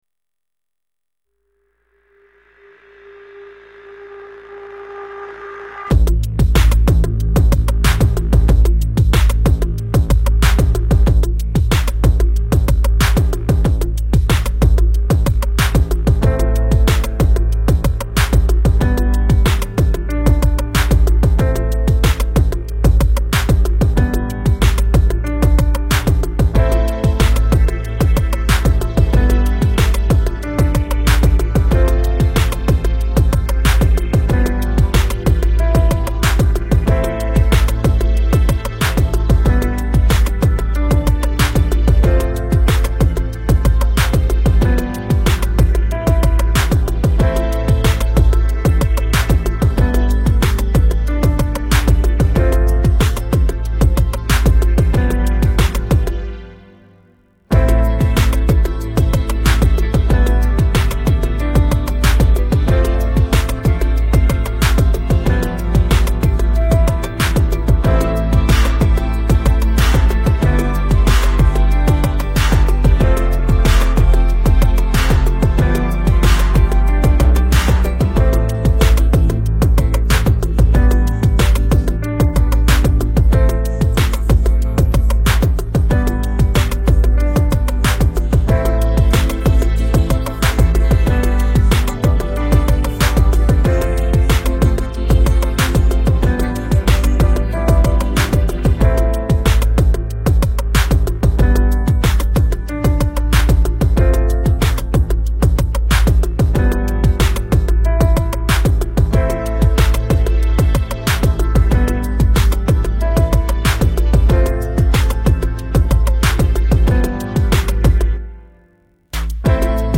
背景音樂